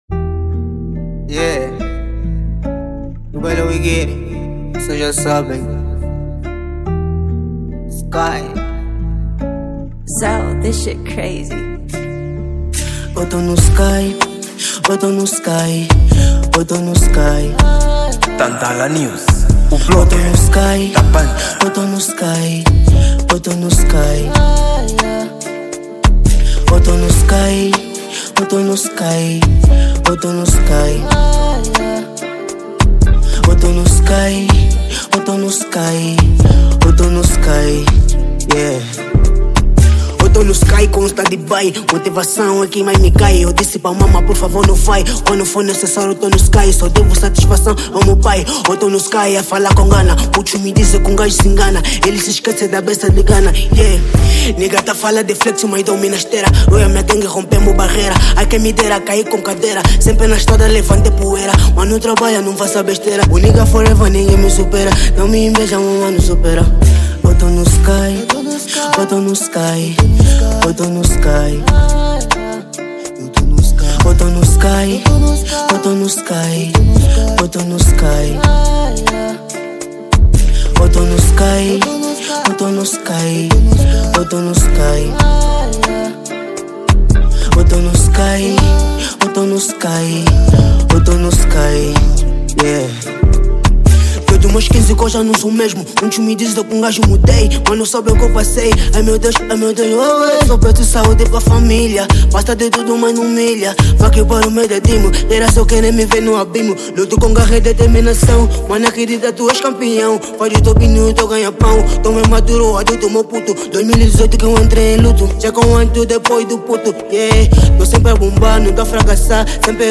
Gênero: Trap